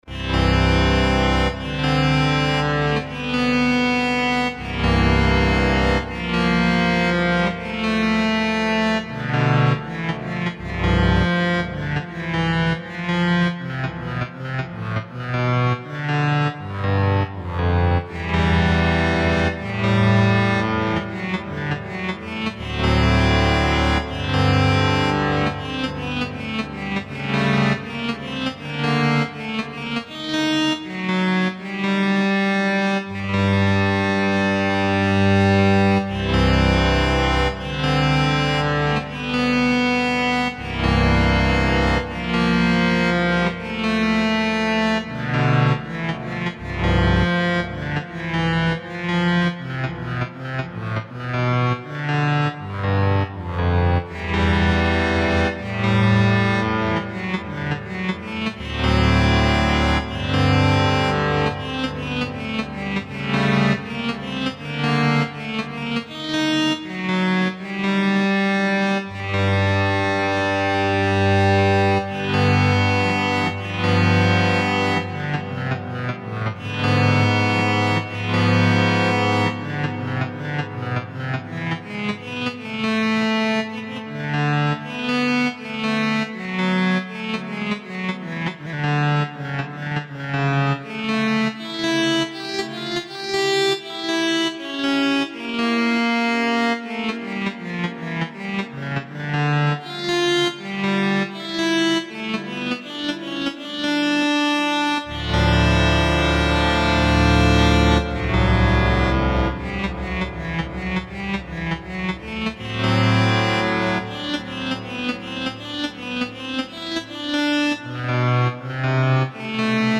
Bach Synthesizer